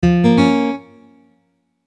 Mobile Taxi - звук SMS (СМС), звук в такси, звук у таксистов, звук заказов в такси, звук уведомления сообщения в машине, Украина, Киев, тынь дынь
В нем был еще один звук из этого приложения, который тоже нередко звучит в машинах такси: